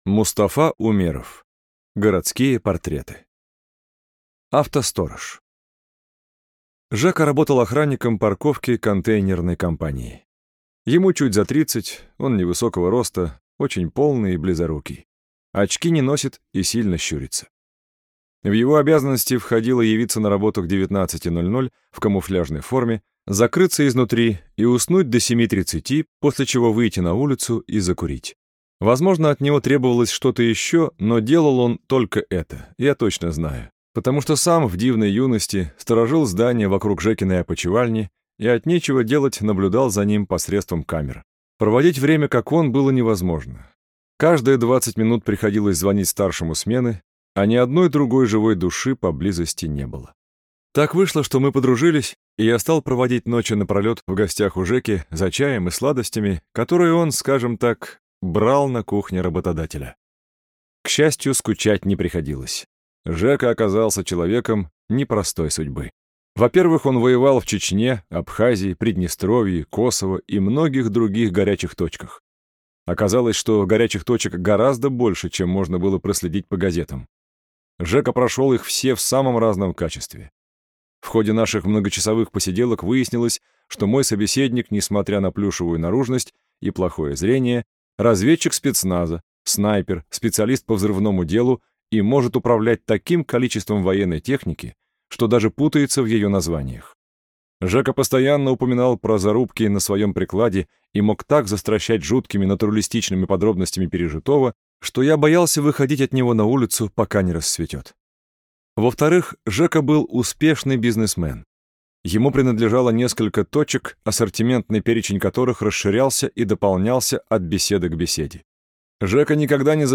Аудиокнига Городские портреты | Библиотека аудиокниг